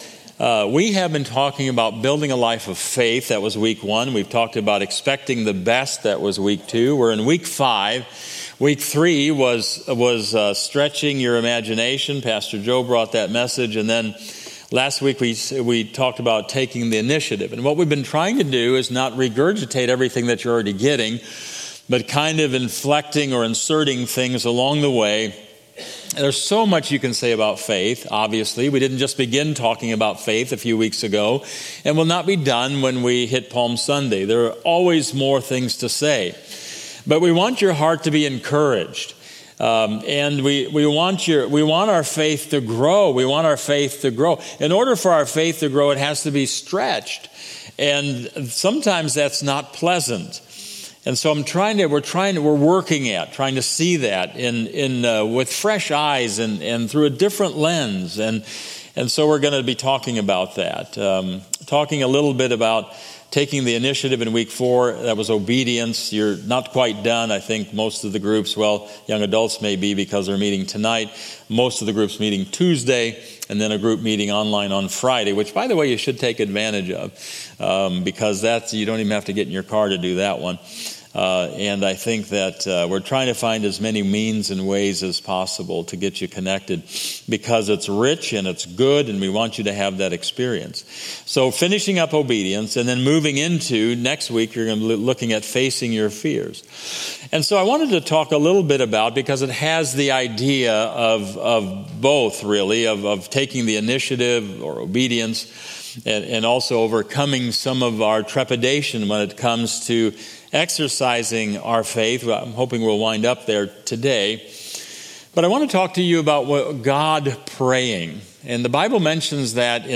Sermon-3-30-25.mp3